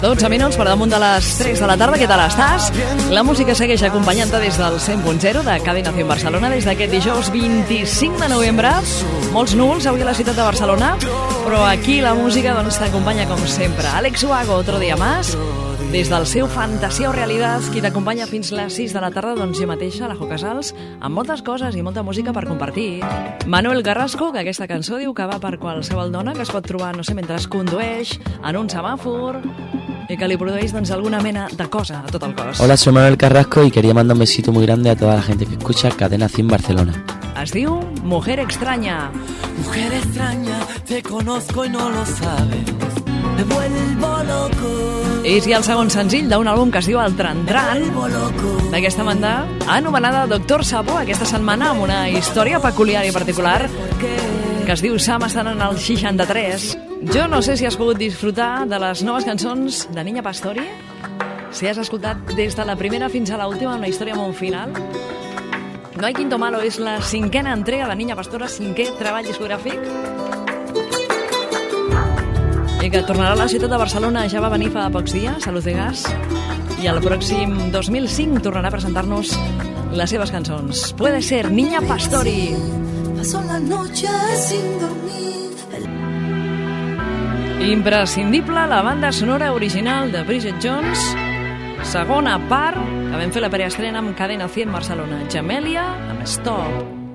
Radiofórmula musical. Identificació de l'emissora, hora, data, estat del tems, salutació del cantant Manuel Carrasco i presentació de temes musicals
Musical
FM